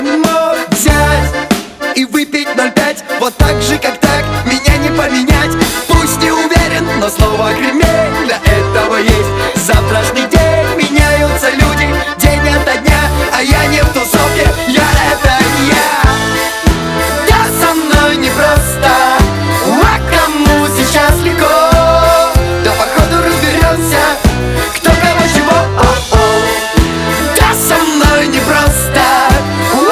Folk-Rock